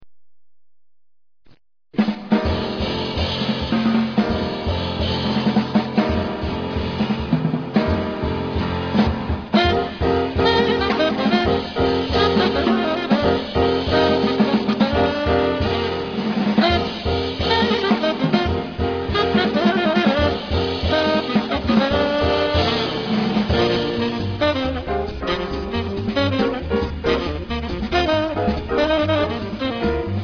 one of the hottest tenor quartet recordings in a long time